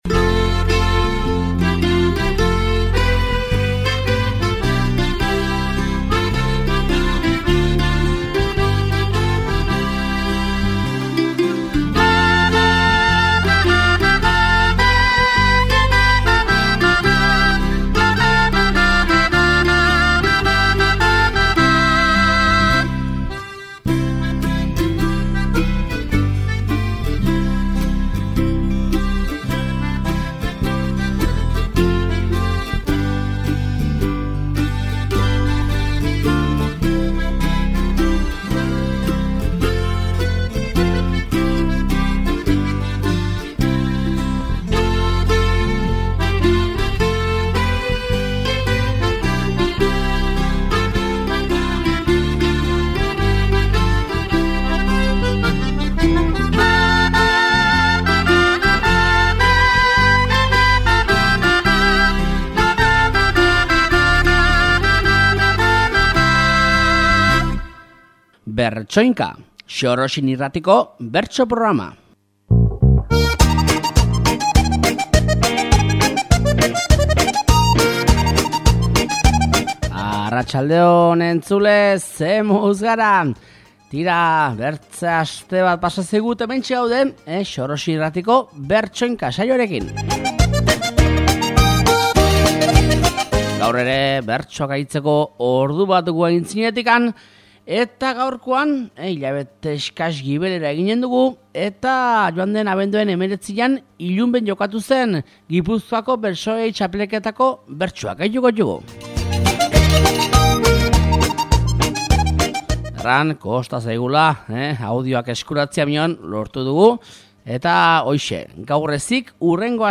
Gipuzkoako bertsolari txapelketako finaleko bertsoak aditzeko aukera izanen dugu aste honetako Bertsoinka saioan